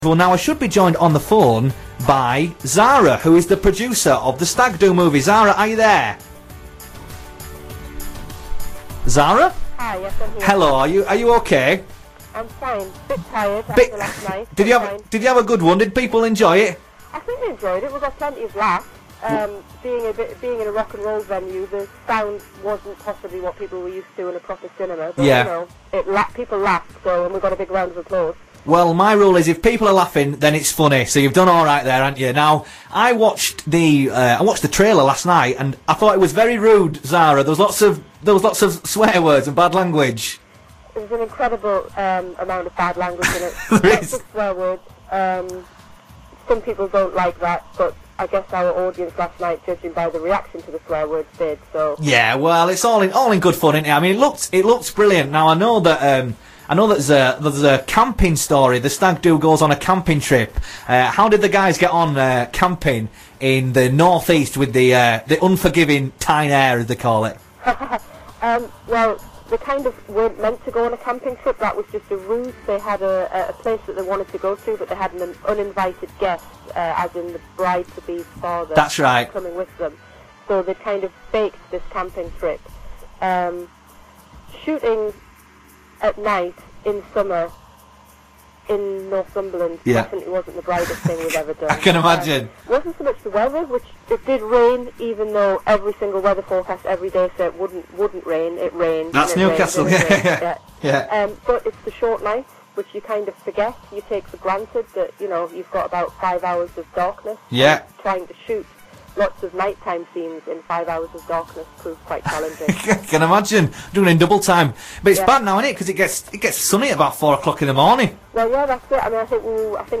Stagg Do interview on The NE1fm Breakfast Show